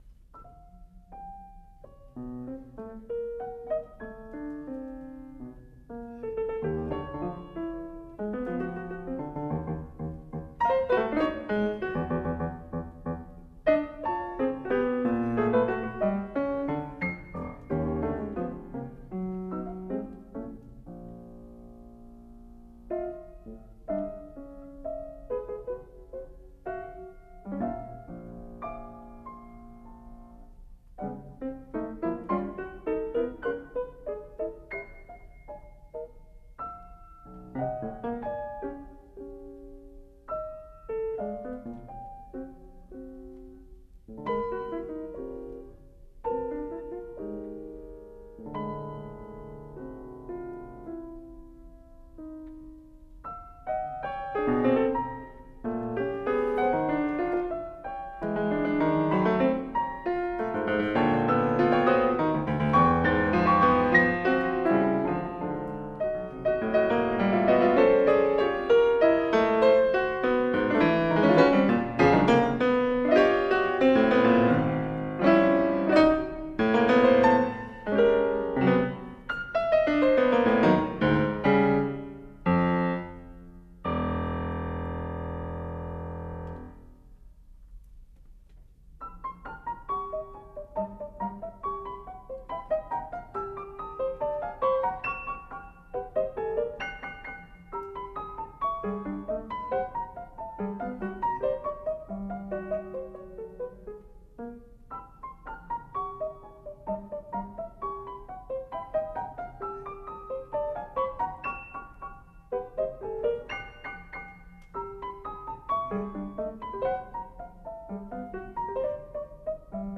2. Gavotte (etwas langsam) - Musette (rascher) - Gavotte da capo.mp3 — Laurea Triennale in Scienze e tecnologie della comunicazione
2-gavotte-etwas-langsam-musette-rascher-gavotte-da.mp3